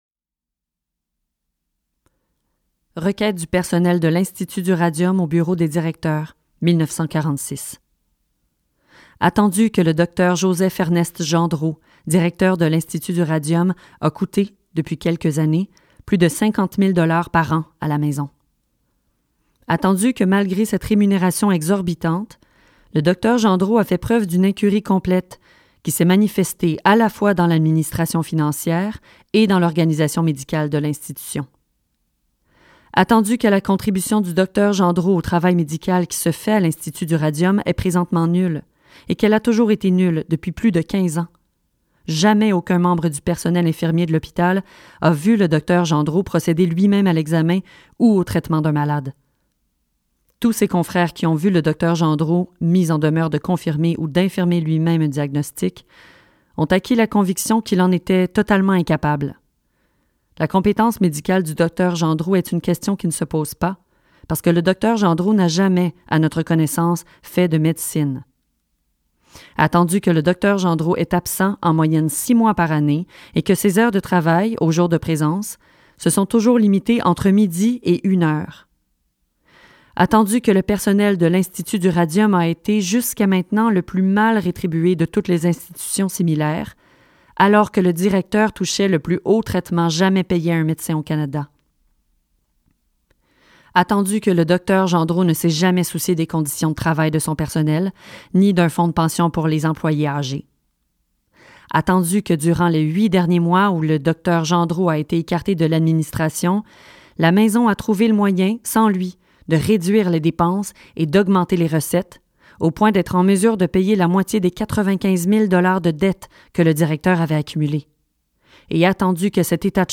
Narrated